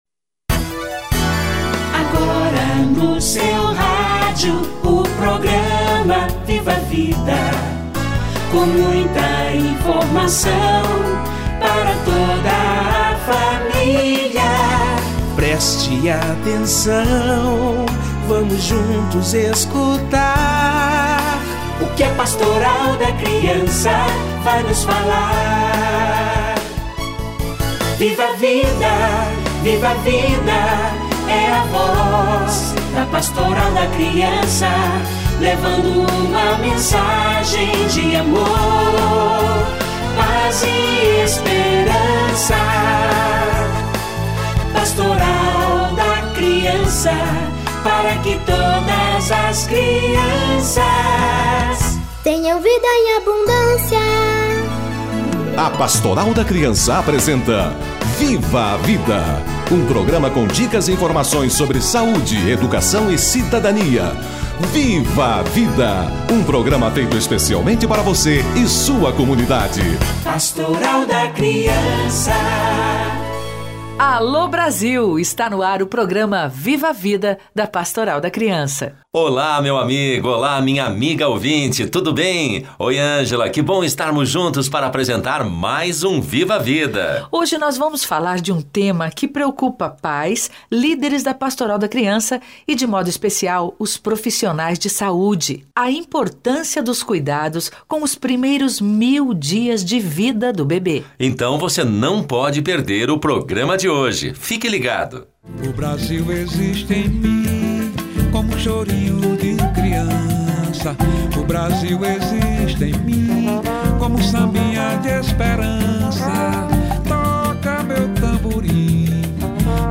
Toda gestação dura 1000 dias - Entrevista